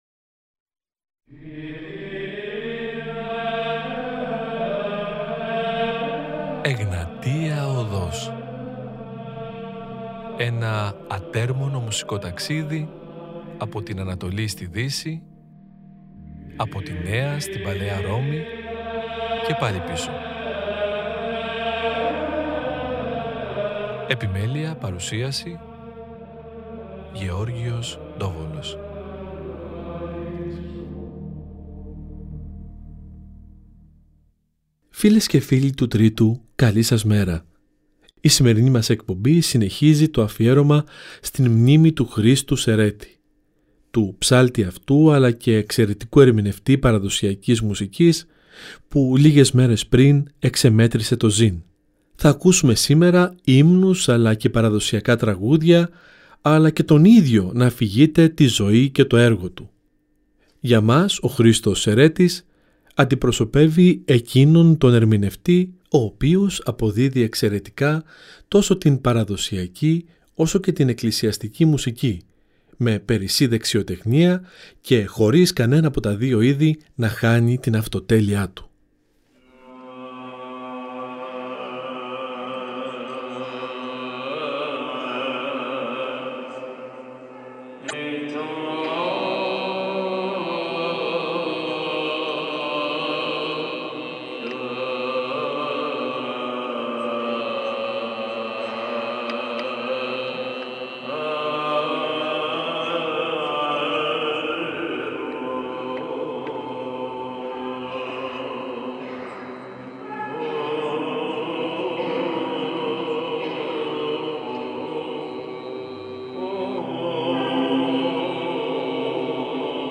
Βυζαντινη Μουσικη